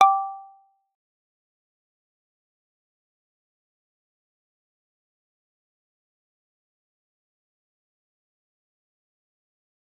G_Kalimba-G5-f.wav